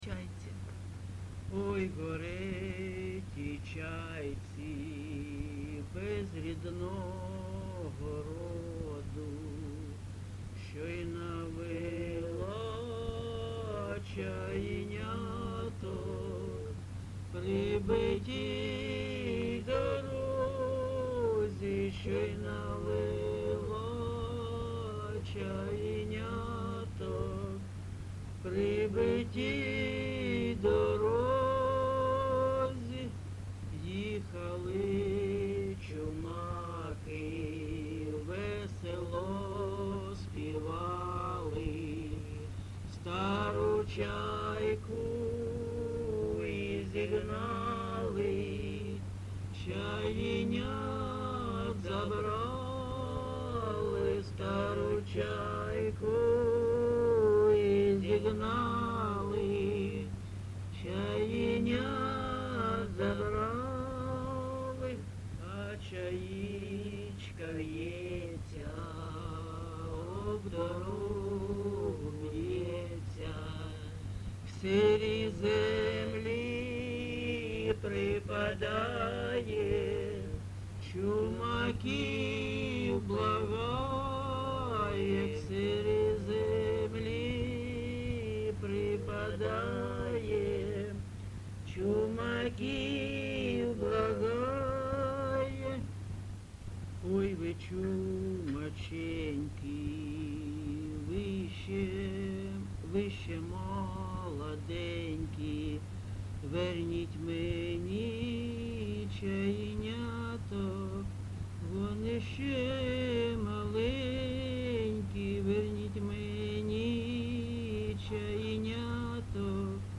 ЖанрПісні з особистого та родинного життя, Чумацькі
Місце записус-ще Щербинівка, Бахмутський район, Донецька обл., Україна, Слобожанщина